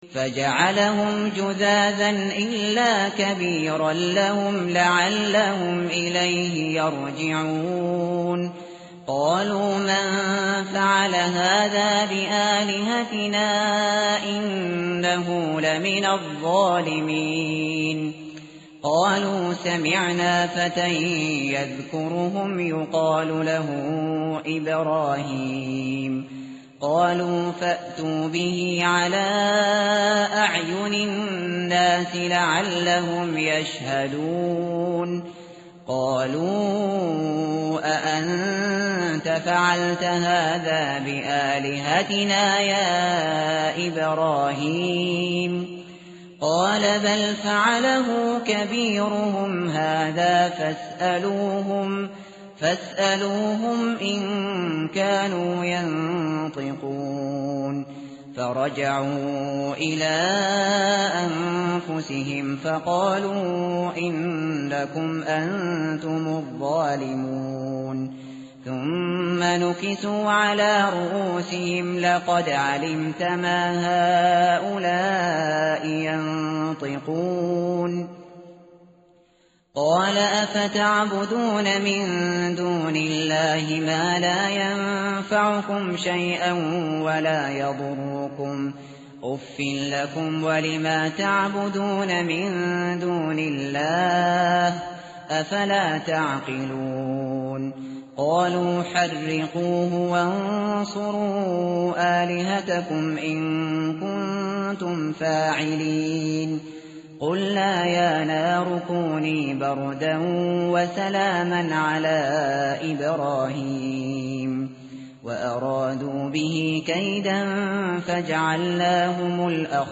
tartil_shateri_page_327.mp3